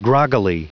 Prononciation audio / Fichier audio de GROGGILY en anglais
Prononciation du mot : groggily